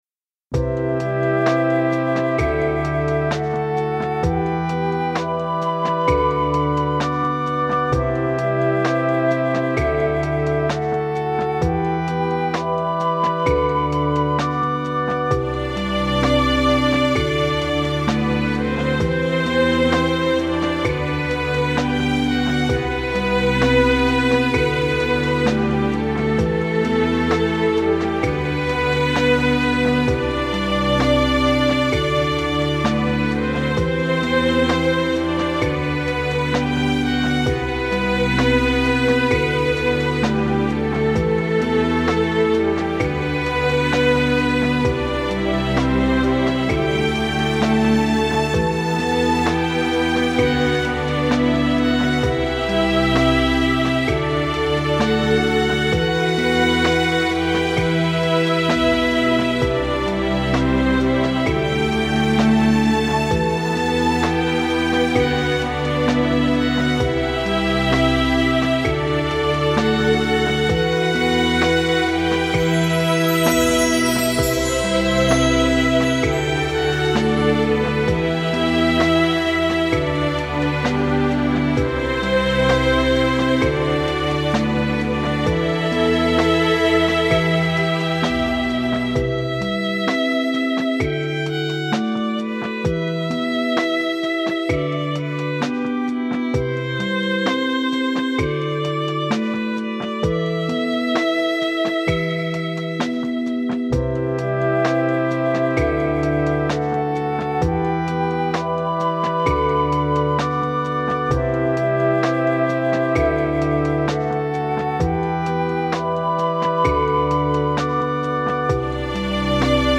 2018.08 | 癒し | ニューエイジ | 3分50秒/3.51 MB